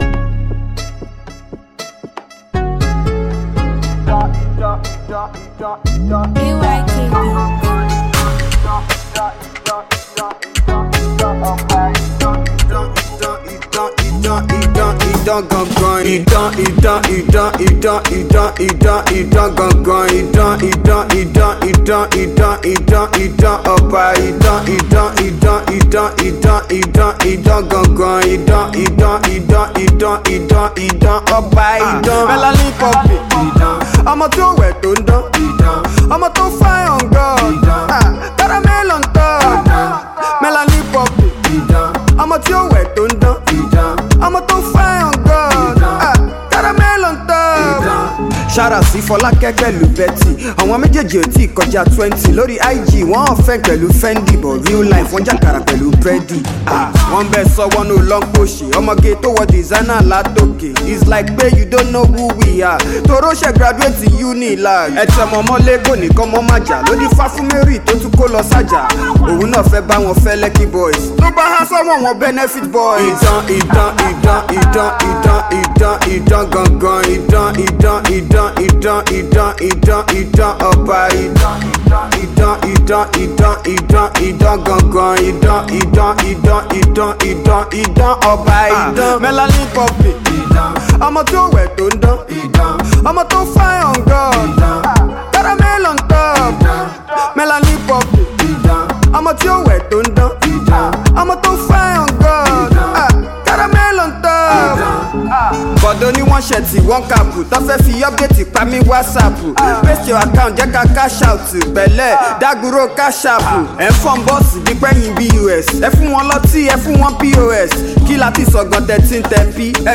Nigerian Indigenous wordsmith